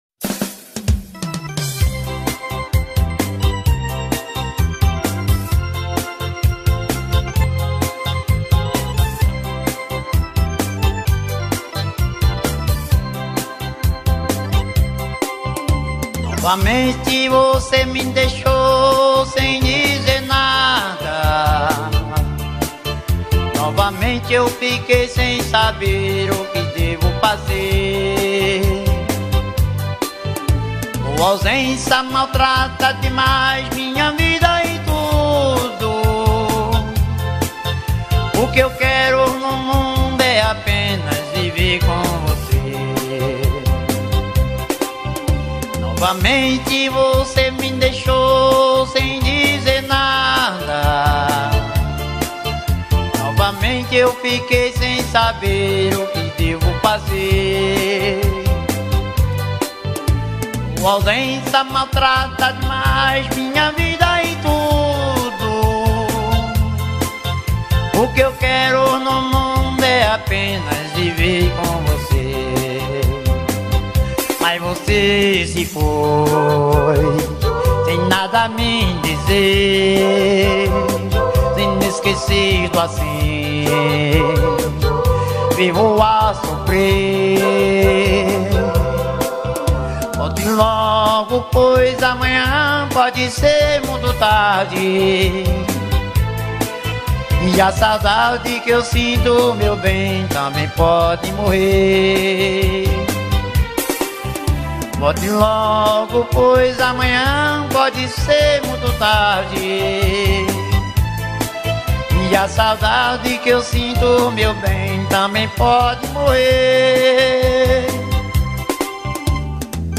2025-01-03 17:56:52 Gênero: Sertanejo Views